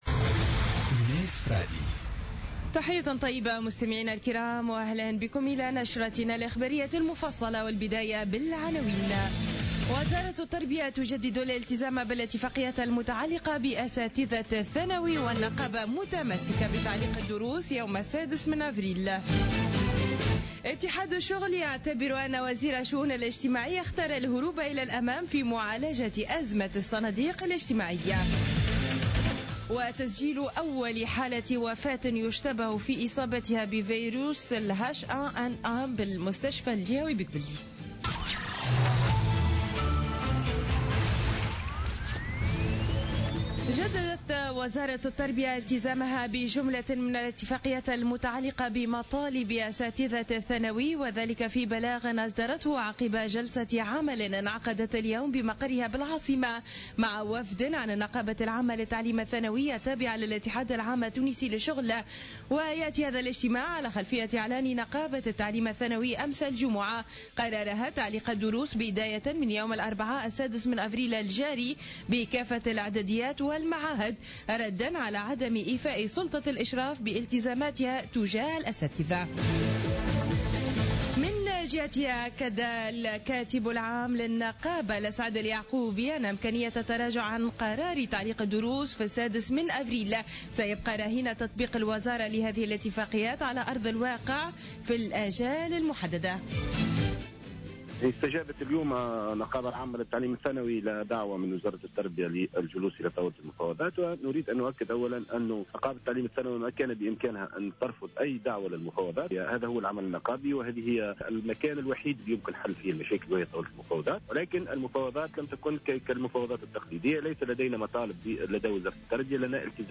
نشرة أخبار السابعة مساء ليوم السبت 02 أفريل 2016